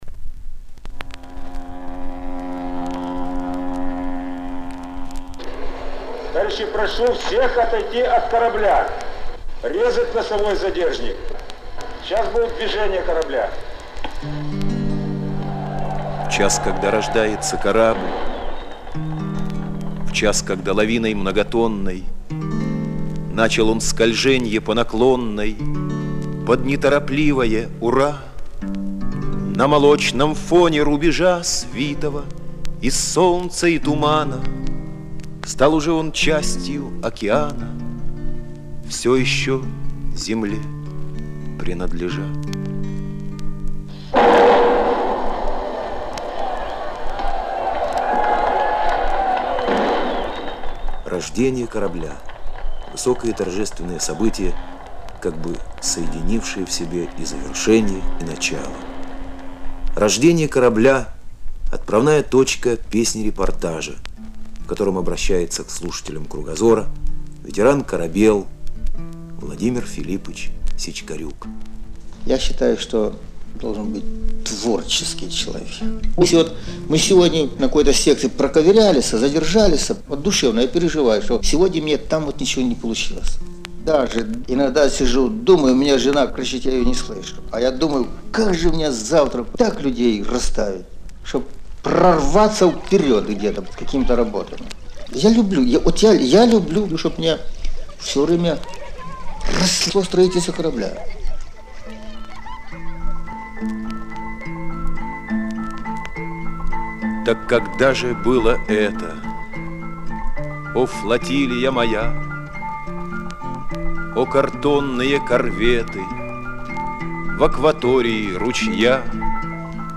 Друзья, любители бардовской песни, помогите!
в рубрике «Песня – репортаж»